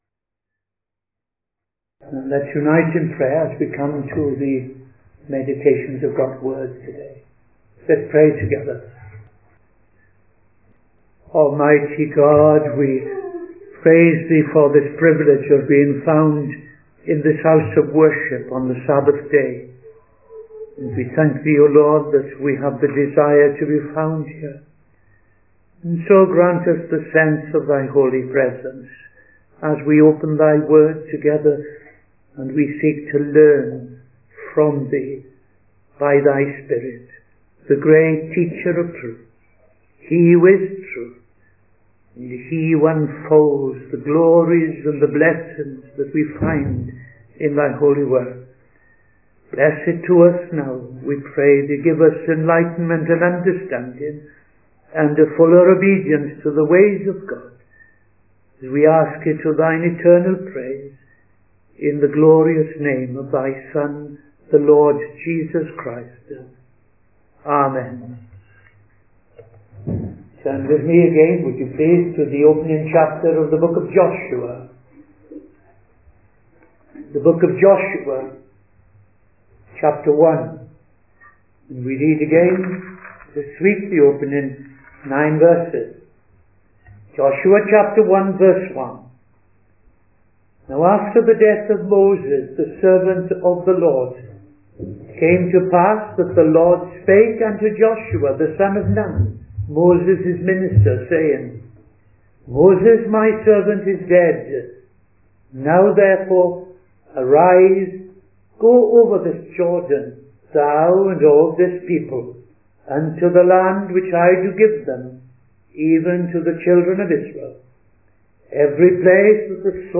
Midday Sermon 7th September 2025